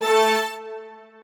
strings8_17.ogg